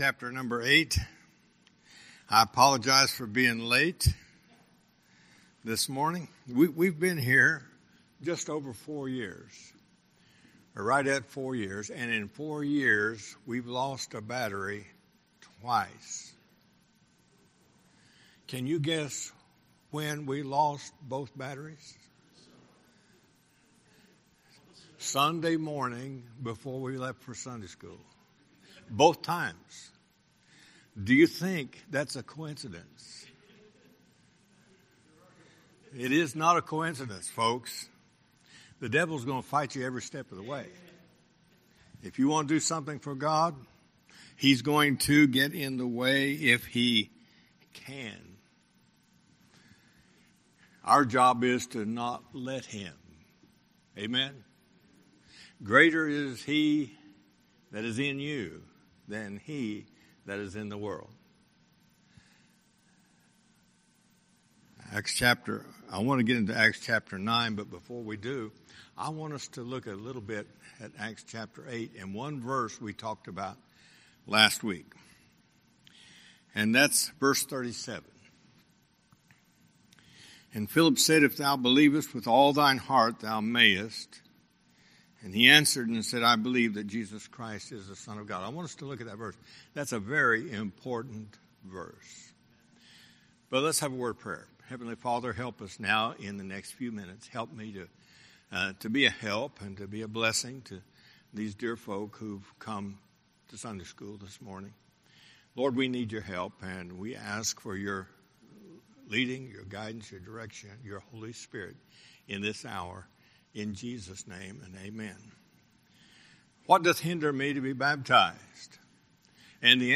Series: Guest Speaker